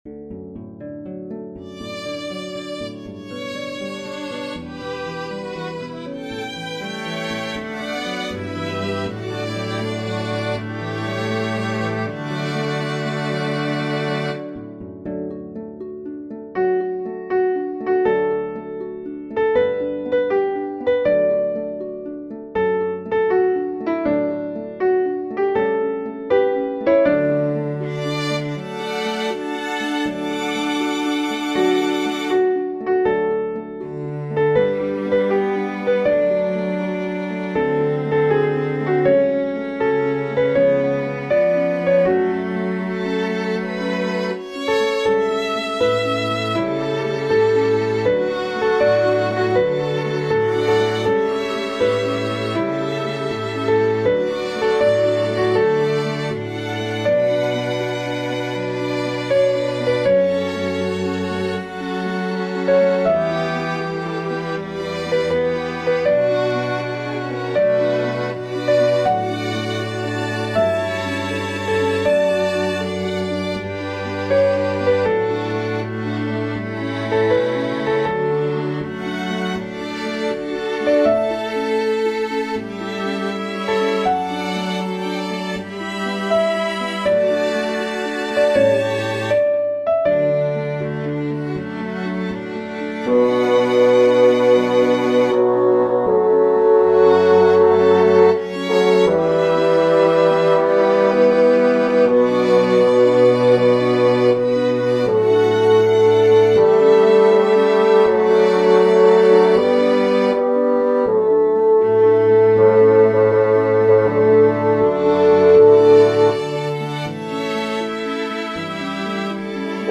There will be two verses.
In these recordings, the soprano soloist sounds like a grand piano. The featured voice sounds like a bassoon.
o-holy-night-key-of-d-bass-3.mp3